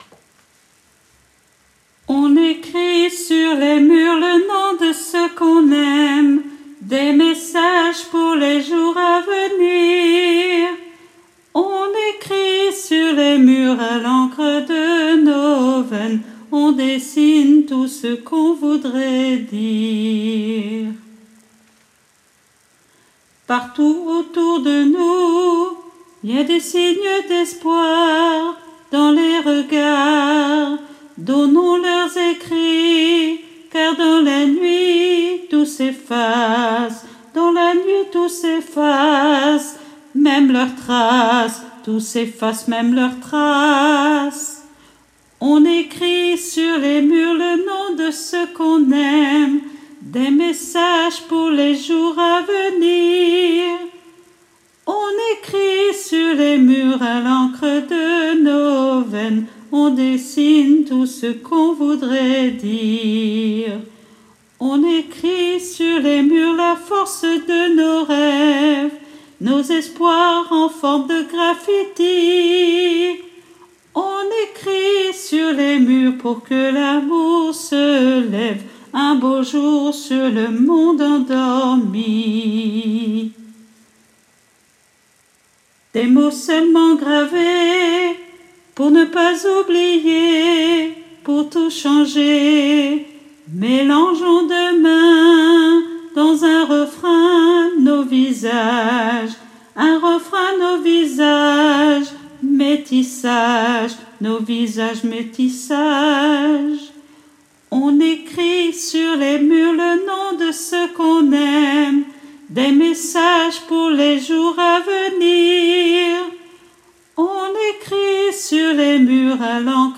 MP3 versions chantées
Soprano